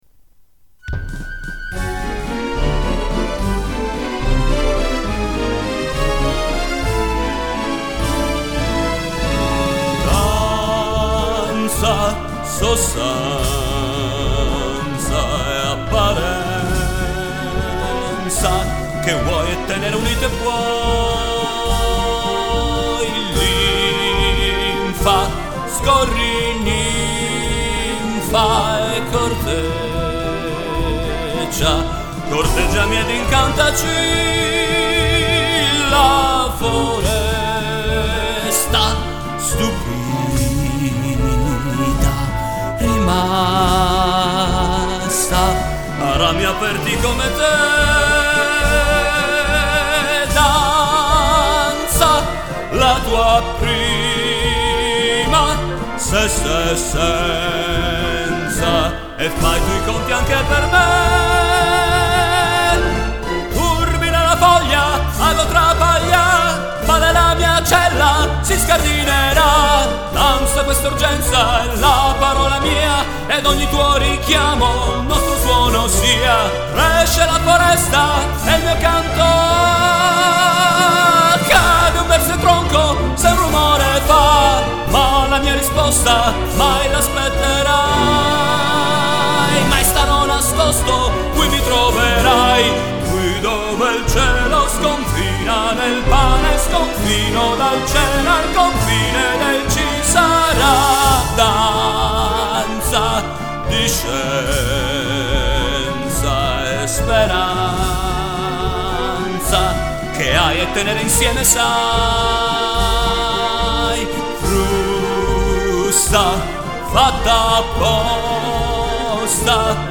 Melologo e pantomima musicale